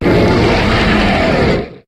Cri de Zygarde dans sa forme 50 % dans Pokémon HOME.